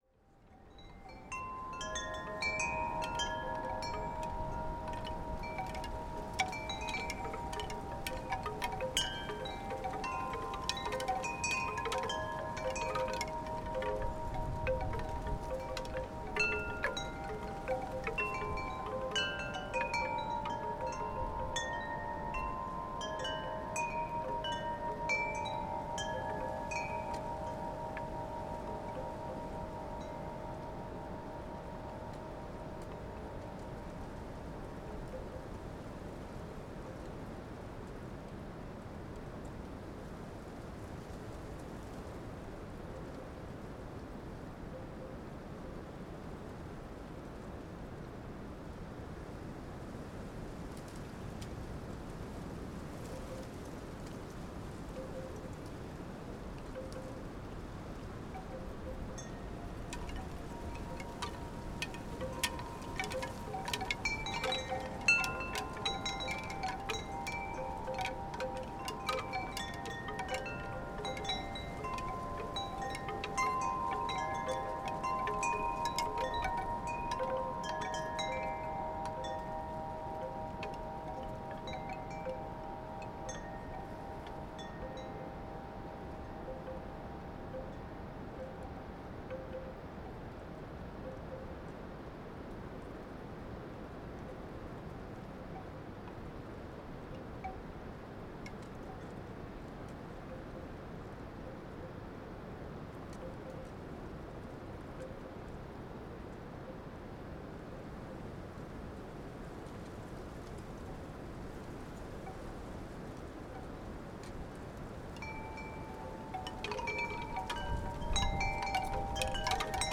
Wind Chimes, Teign Gorge near Castle Drogo - Bamboo - Gregorian - excerpt
Category 🌿 Nature
autumn bamboo Castle-Drogo chimes Devon England field-recording Gregorian-Chimes sound effect free sound royalty free Nature